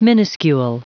Prononciation du mot minuscule en anglais (fichier audio)
Prononciation du mot : minuscule